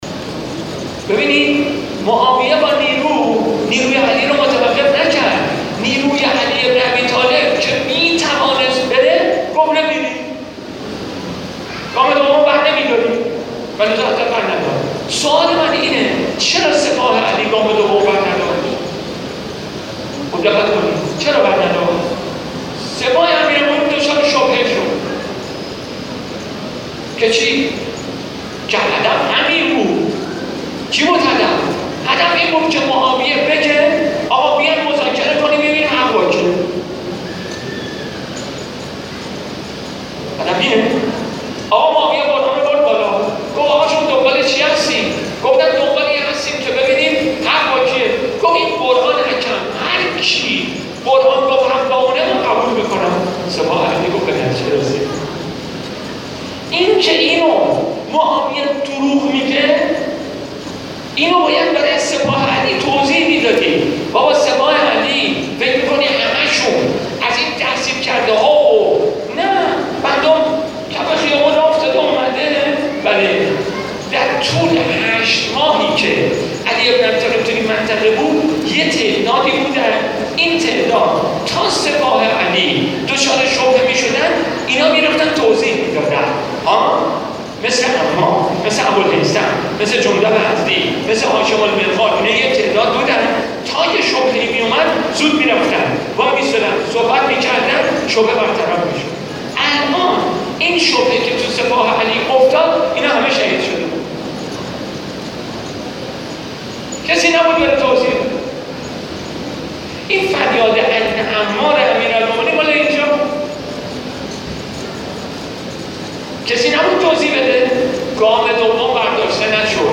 به گزارش خبرنگار خبرگزاری رسا در کاشان حجت الاسلام والمسلمین مهدی طائب در نشست روشنگری و گفتمان سازی فرهنگی انقلابی که چهارشنبه شب در آستان مقدس محمد هلال ابن علی (ع) شهرستان آران و بیدگل برگزار شد، گفت: امروز وظیفه همه ما گفتمان سازی است.